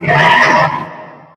sounds / monsters / poltergeist / attack_2.ogg